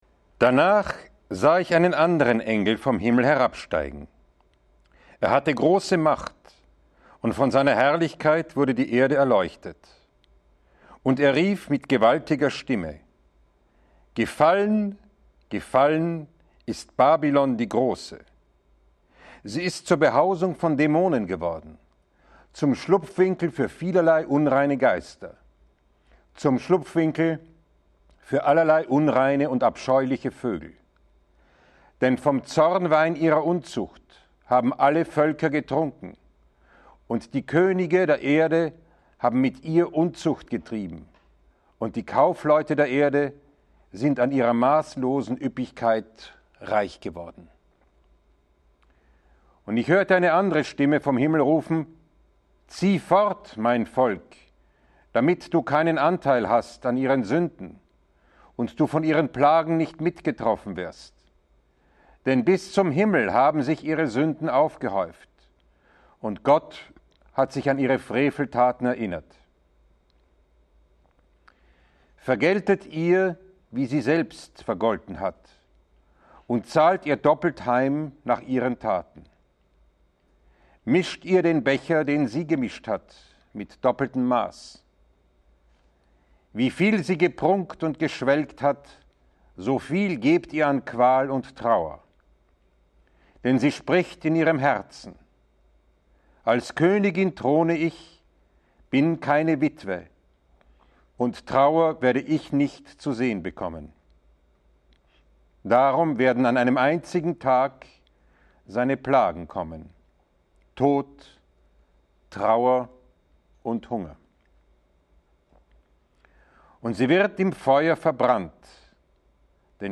Die Offenbarung des Johannes Kapitel 18 – 22,21 ~ Lesungen aus der Heiligen Schrift Podcast